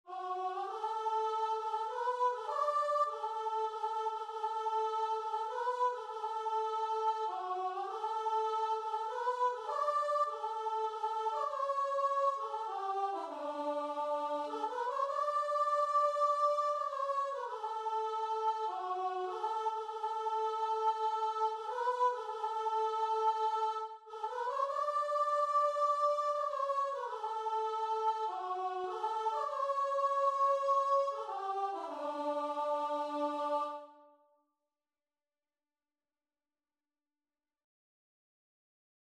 Christian Christian Guitar and Vocal Sheet Music I Will Sing the Wondrous Story
Free Sheet music for Guitar and Vocal
D major (Sounding Pitch) (View more D major Music for Guitar and Vocal )
3/4 (View more 3/4 Music)
Classical (View more Classical Guitar and Vocal Music)